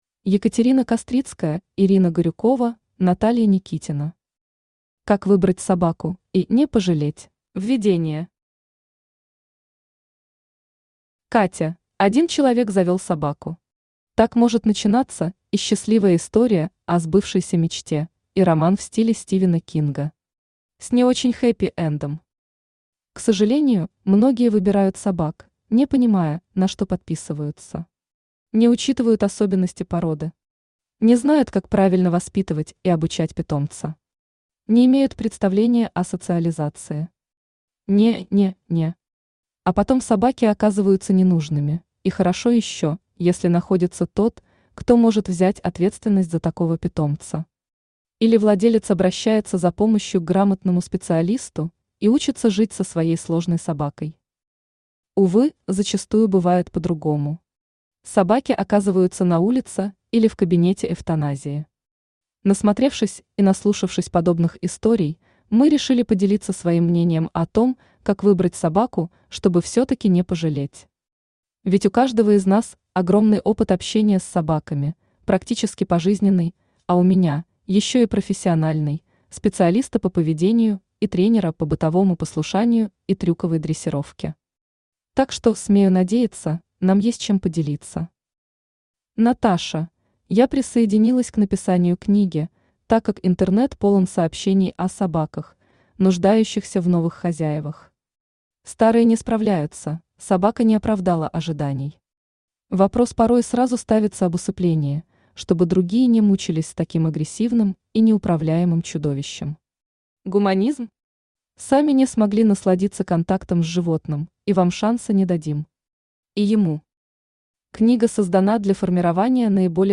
Аудиокнига Как выбрать собаку и (не) пожалеть | Библиотека аудиокниг
Aудиокнига Как выбрать собаку и (не) пожалеть Автор Екатерина Кастрицкая Читает аудиокнигу Авточтец ЛитРес.